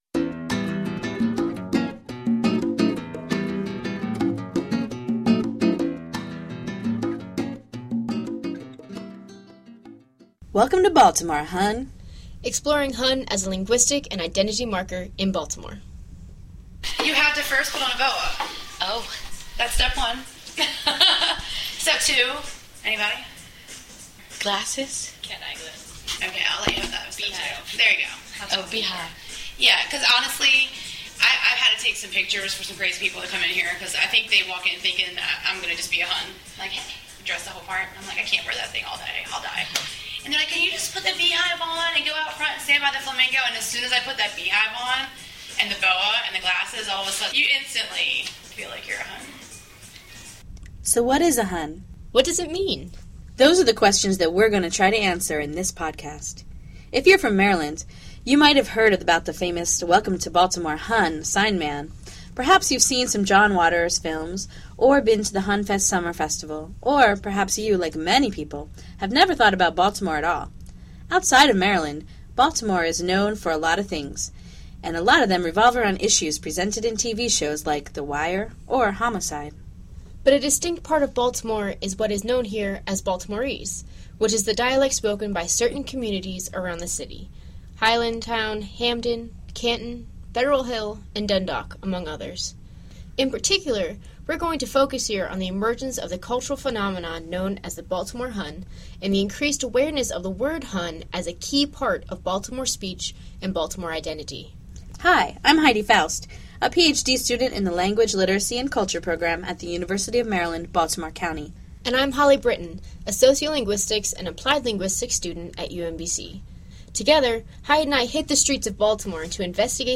We decided to take to the streets of Baltimore neighborhoods to investigate the word “hon.” We brainstormed questions, such as: Who uses the word “hon”? With whom? When? What characteristics are associated with the Hon identity?
As you listen to this podcast, you’ll hear that the use of the word “hon” is changing and evolving.